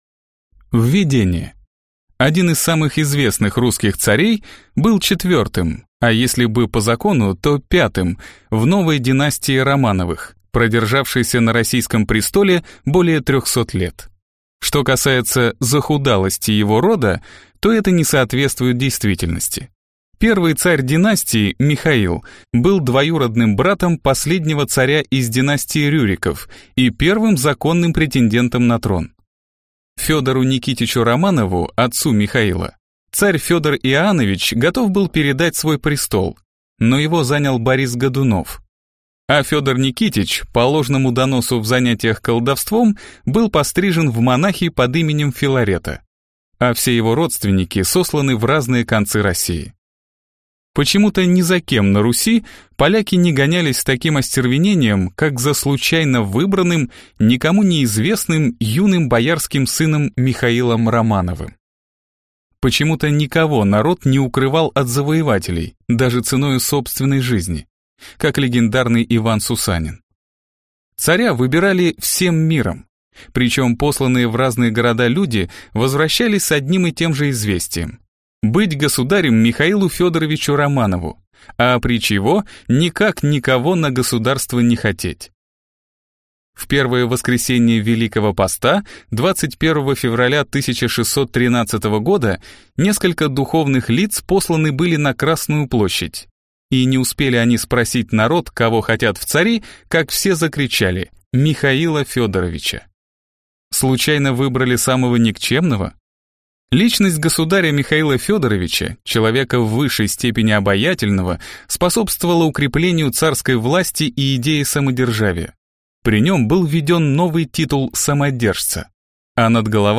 Аудиокнига Петр Первый | Библиотека аудиокниг